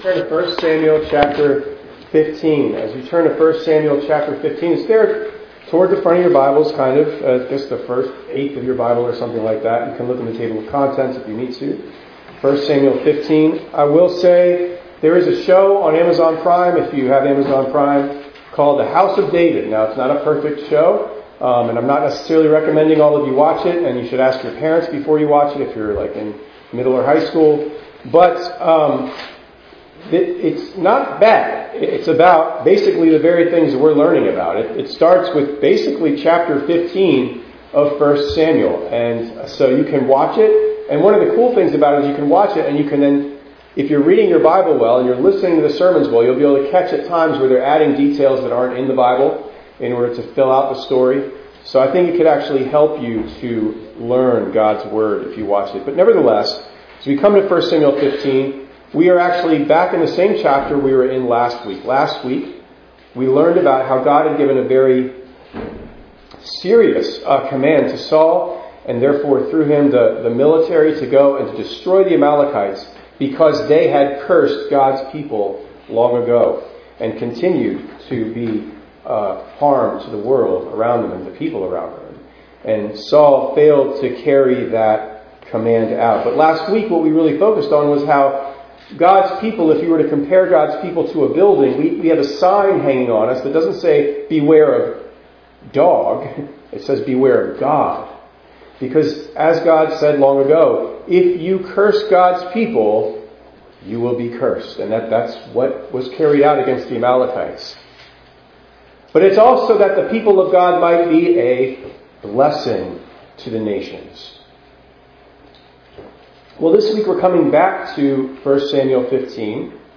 3_23_25_ENG_Sermon.mp3